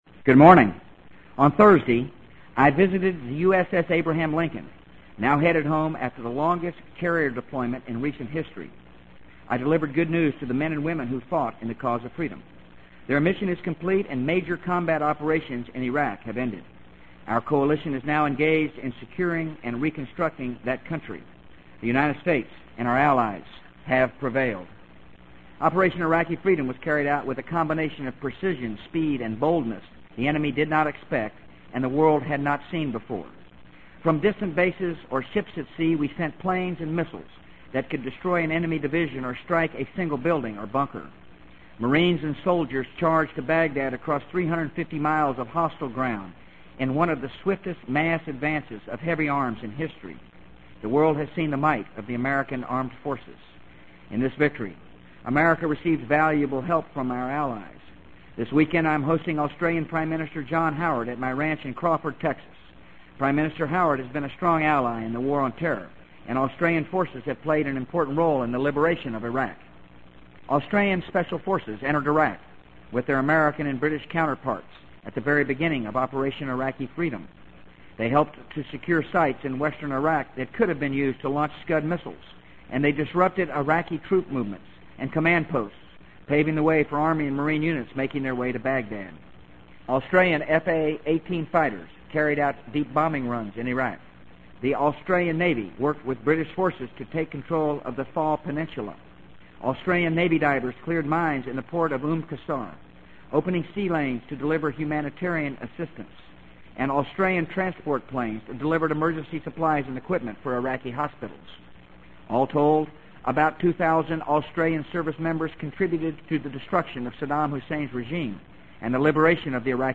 【美国总统George W. Bush电台演讲】2003-05-03 听力文件下载—在线英语听力室